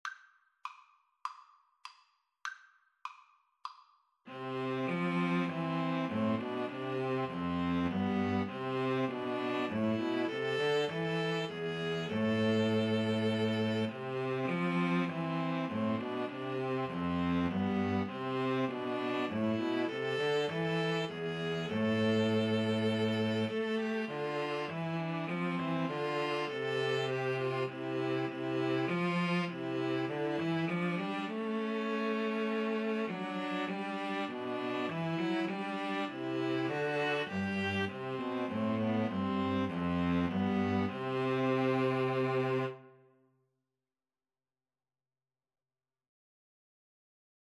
Play (or use space bar on your keyboard) Pause Music Playalong - Player 1 Accompaniment Playalong - Player 3 Accompaniment reset tempo print settings full screen
C major (Sounding Pitch) (View more C major Music for String trio )